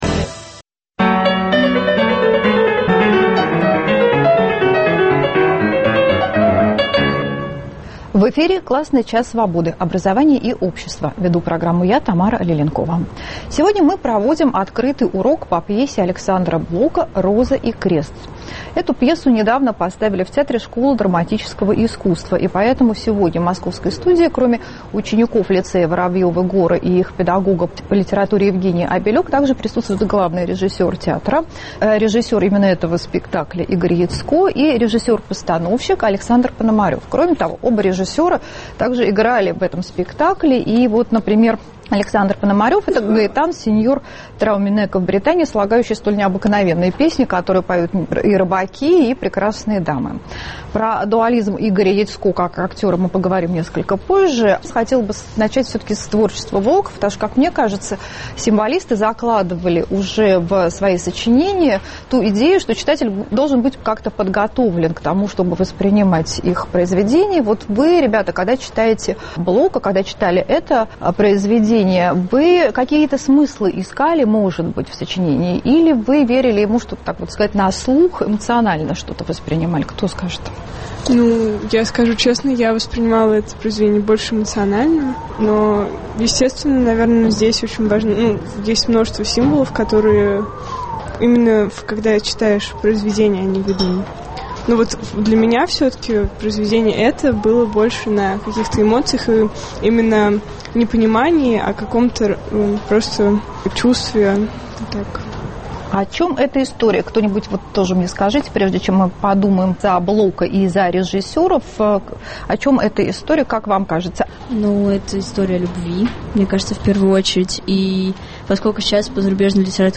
Открытый урок по пьесе А.Блока «Роза и Крест»